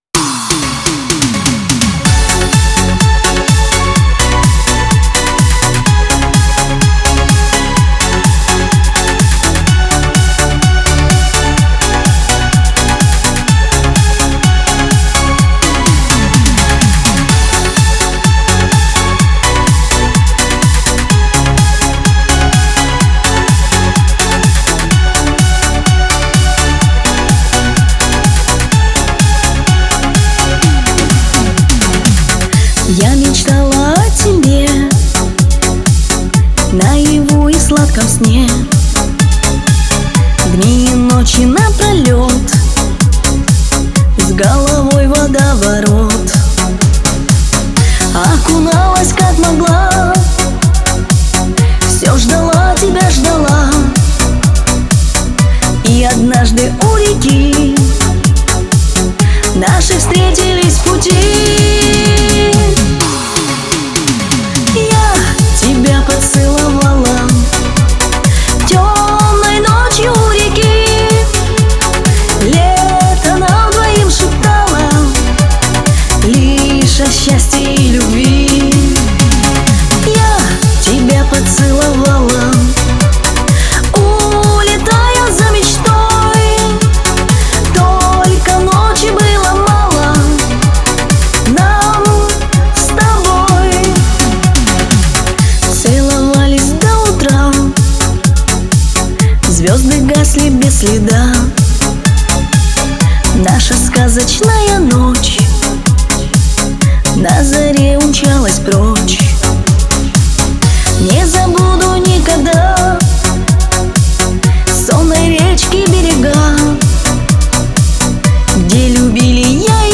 Скачать музыку / Музон / Песни Шансона